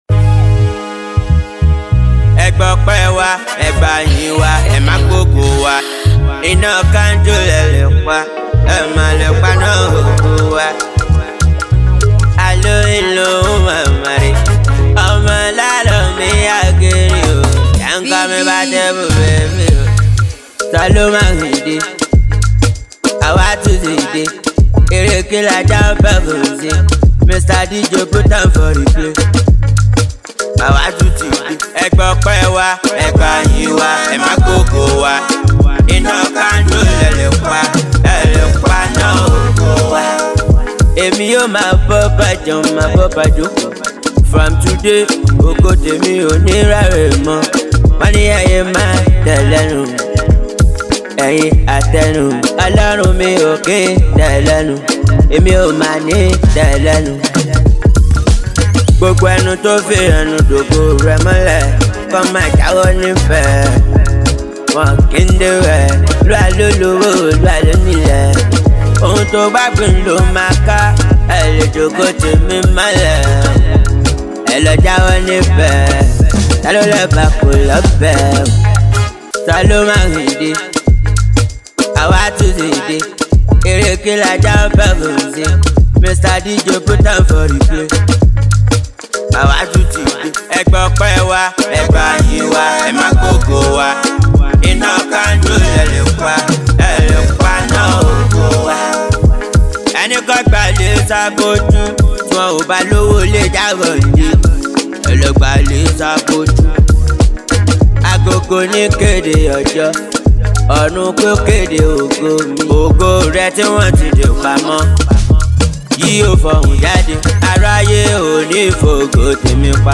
street-hop
Known for his unfiltered lyrics and energetic delivery